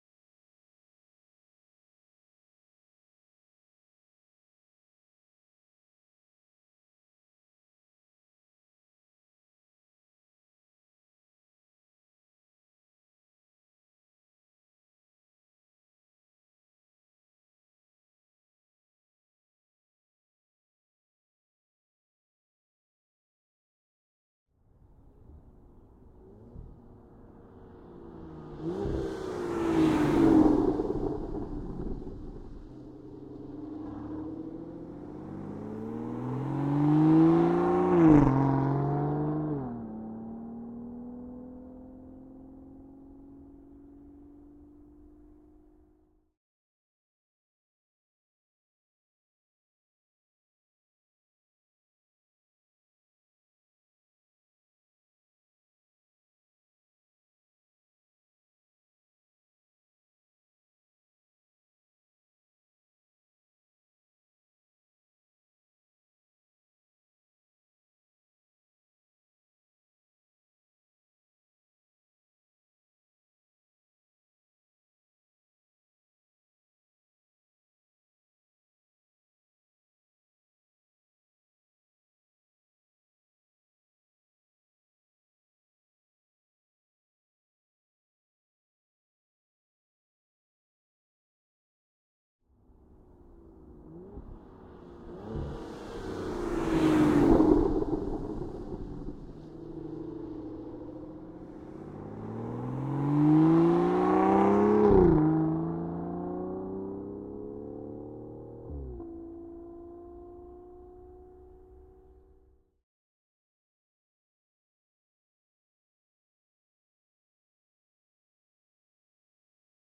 Aston_Martin_DB9_t4_Ext_By_Gearshifts_M10.ogg